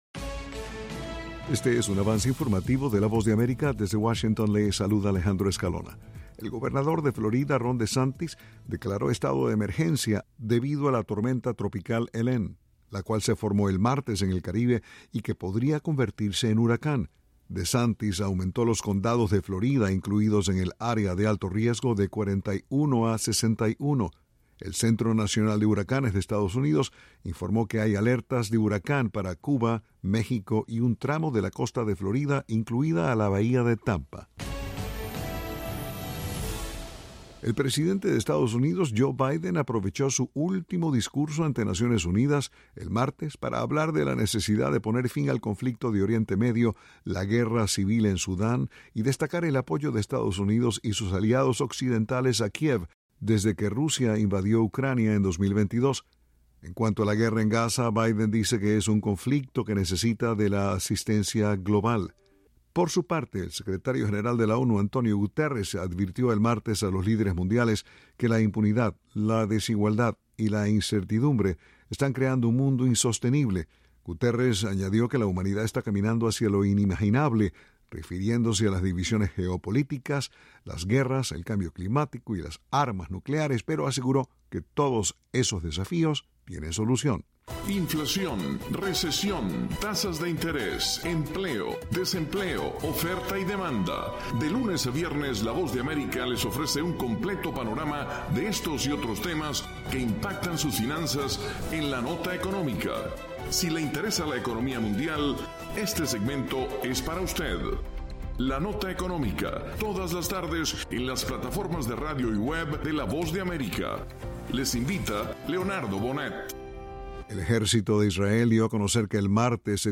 Este es un avance informativo presentado por la Voz de América desde Washington.